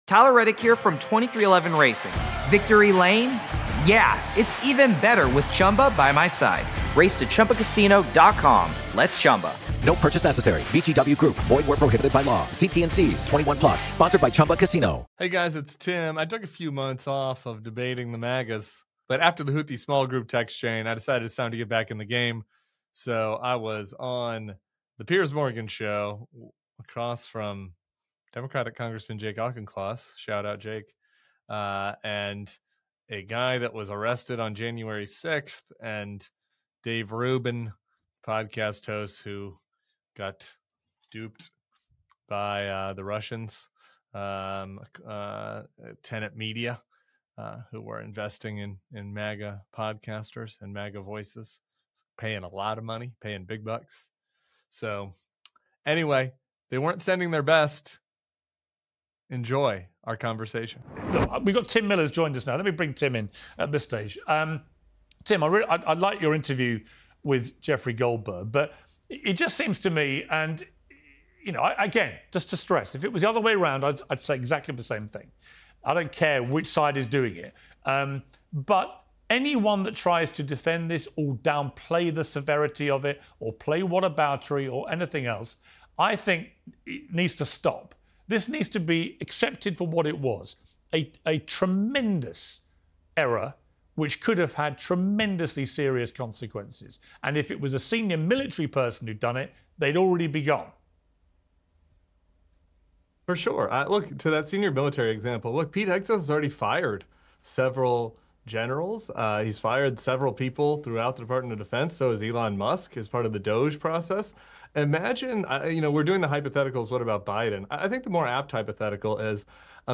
Tim Miller joins a panel on Piers Morgan Uncensored to debate the Trump administration's Signal group chat fiasco and Rep. Crockett's recent comments on Gov. Greg Abbott.